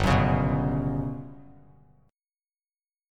GM#11 chord